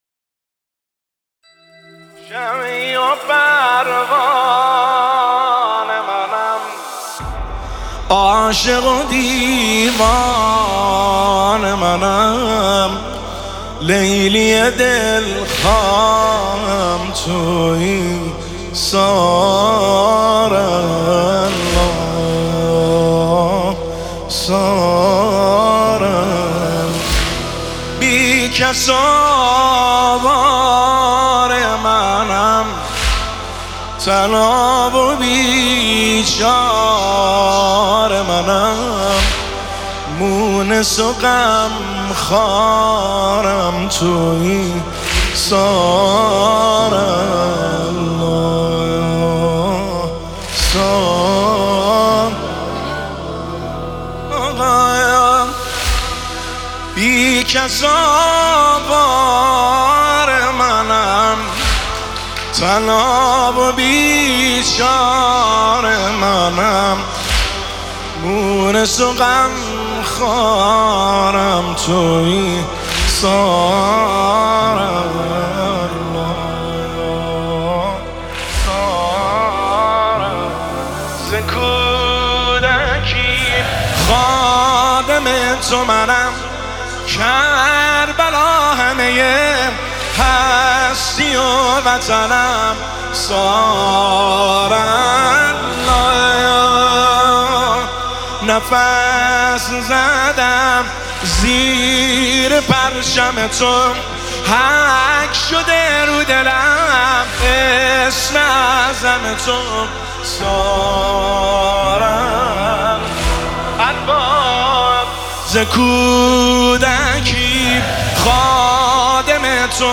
دانلود نماهنگ
نماهنگ-شمعی-و-پروانه-منم.mp3